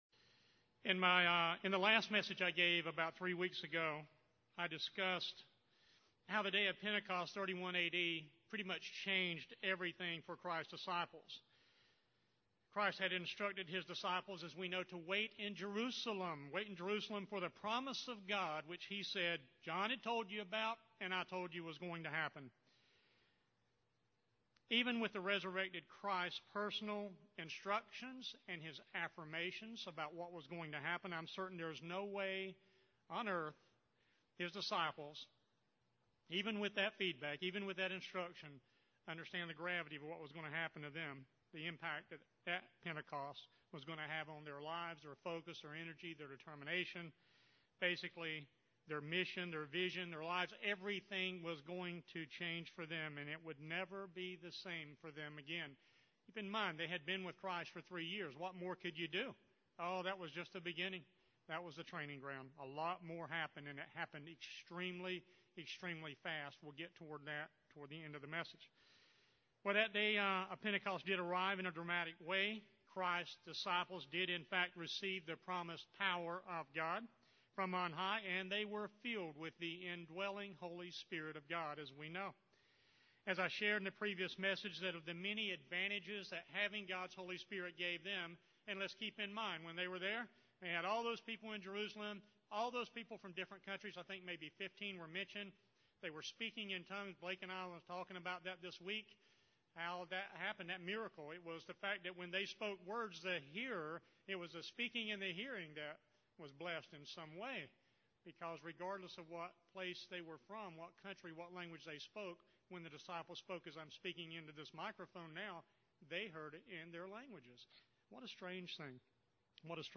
Children of the Father - Part 2 The outpouring of GOD’s Holy Spirit on Pentecost AD 31 fundamentally changed everything for CHRISTs Disciples then, as well as those of us who are HIS disciples today who also have received the Promise of GOD, the gift of GOD’s Holy Spirit. This sermon reviews what I consider the most significant aspect of a person’s receiving the gift of GOD’s Holy Spirit and that is the special Family relationship it offers us with GOD, that through the indwelling Holy Spirit we are in fact now and are to be born fully the very ‘Children of the FATHER’.